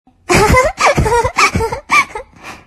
女孩银铃笑声音效_人物音效音效配乐_免费素材下载_提案神器
女孩银铃笑声音效免费音频素材下载